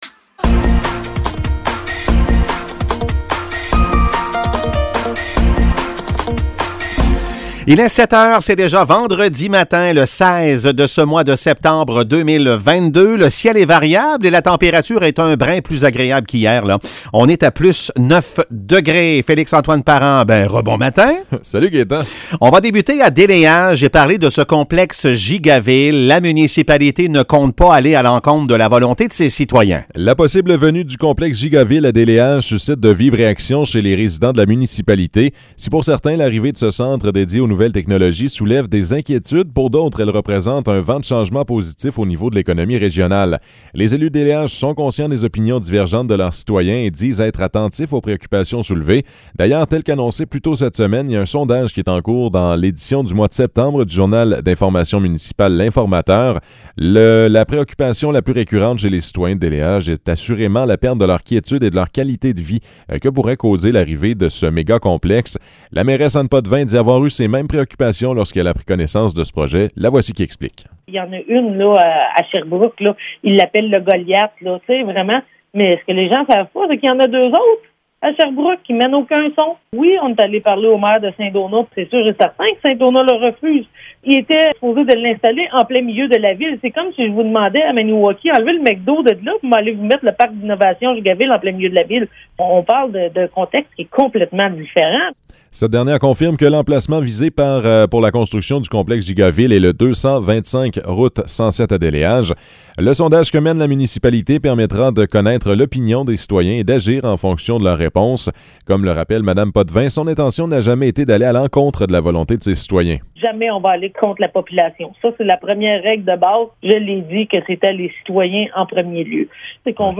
Nouvelles locales - 16 septembre 2022 - 7 h